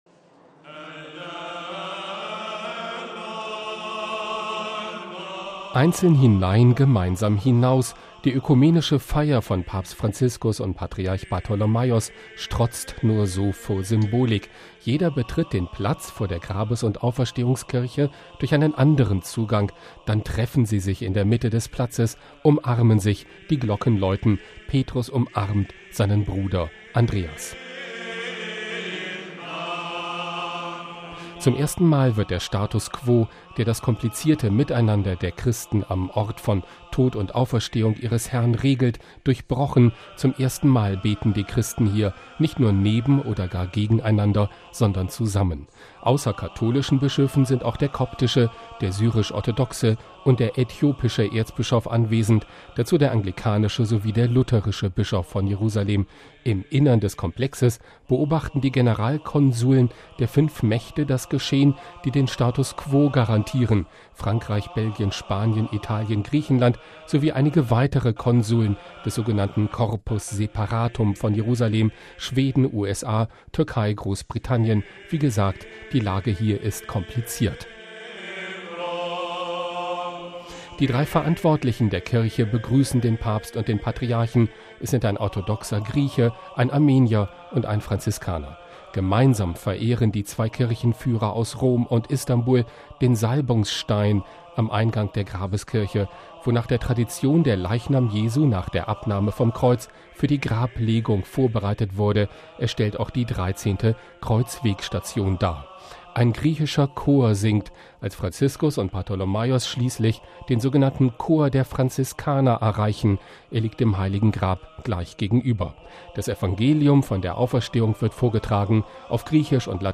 Jeder betritt den Platz vor der Grabes- und Auferstehungskirche durch einen anderen Zugang; dann treffen sie sich in der Mitte des Platzes, umarmen sich, die Glocken läuten.
Ein griechischer Chor singt, als Franziskus und Bartholomaios schließlich den sogenannten Chor der Franziskaner erreichen, der dem Heiligen Grab gleich gegenüberliegt.